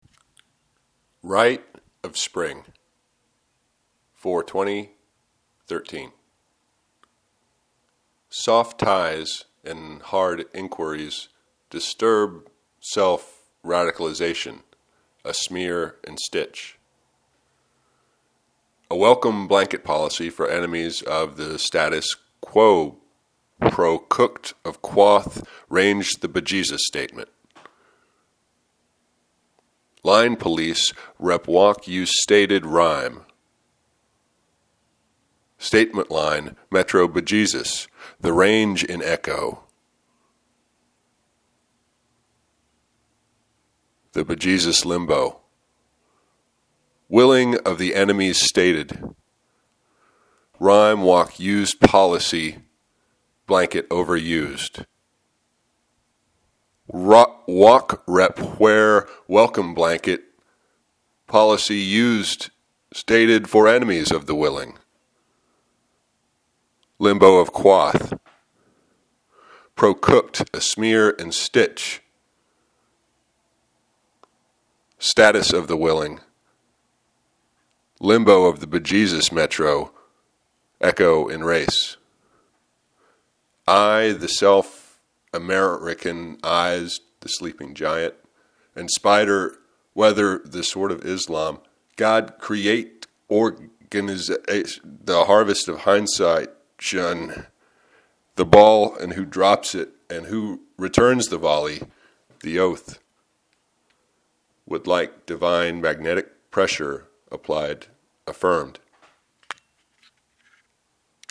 Rite of Spring, Reading 2: